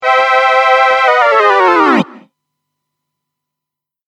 | ghost effect |